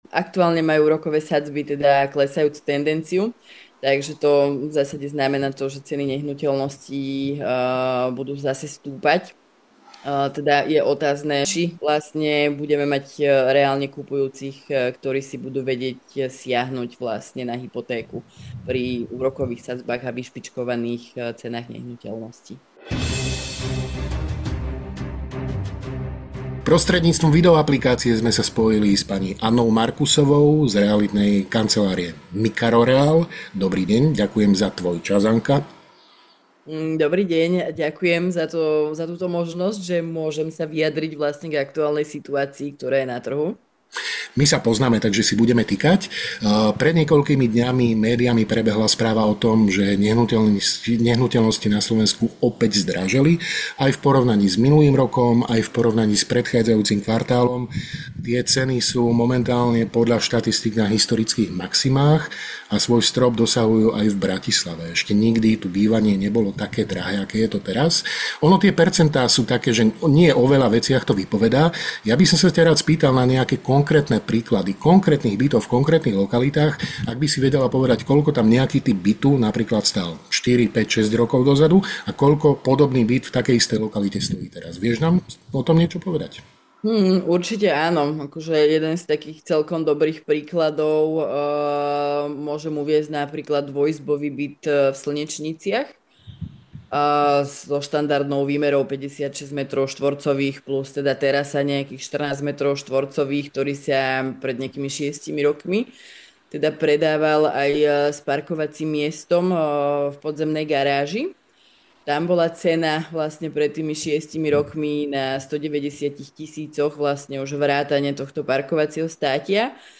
Viac v rozhovore.